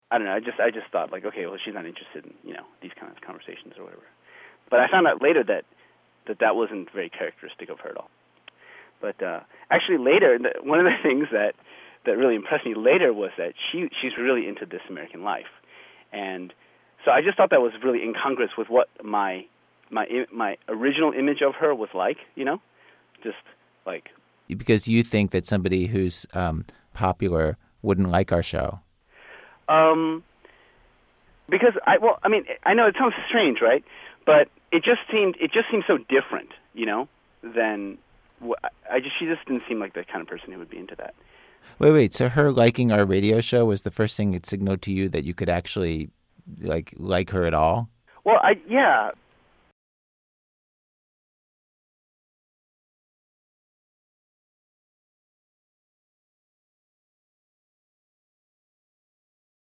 That seemed like something he should probably ask, not me, so we arranged for me to interview him over the phone two days before the speech.